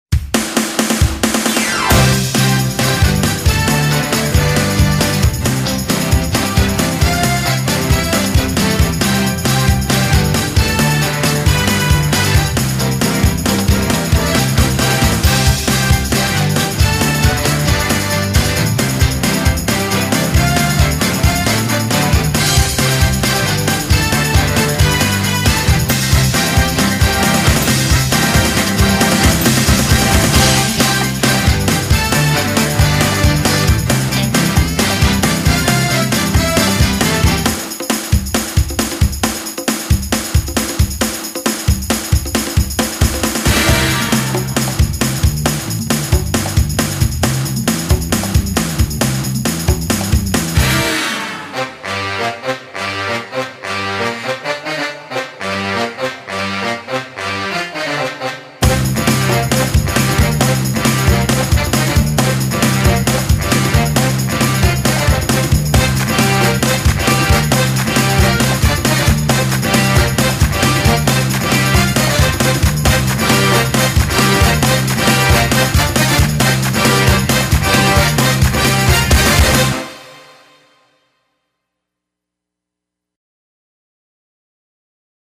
喜庆的